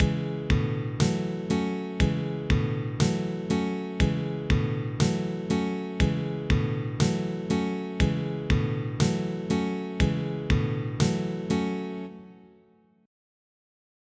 I made the first 4 chords of my first rock song, we all know that the first four chords are the most important.
So I started with a midi guitar.
It's far too simple to be considered a decent song, but I can tell from the chords and the drum that nothing is obviously wrong with it.
However, the sound is not awful, so this digital representation of a guitar chord is reasonably useful.
There are good reasons to have short durations and longer durations, but it makes sense to start simple: 4 quarter notes, all chords, no rests.